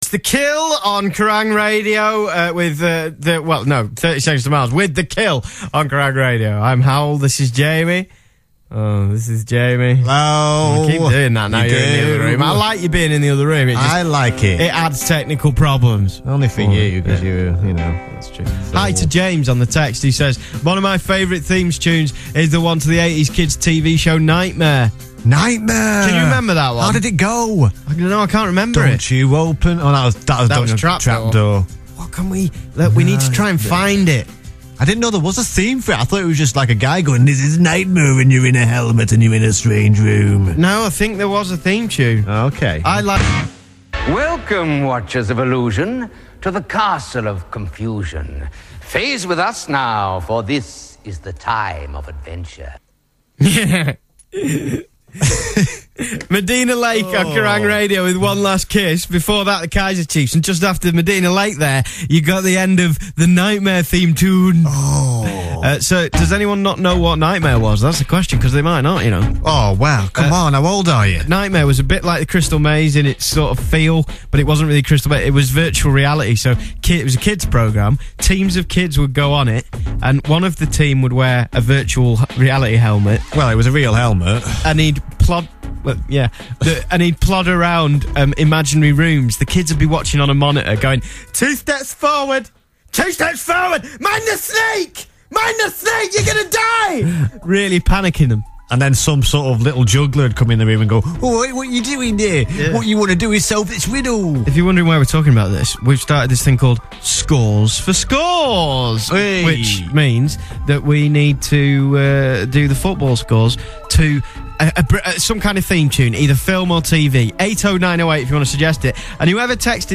Knightmare was mentioned in the Offside show on Kerrang radio, followed by the theme tune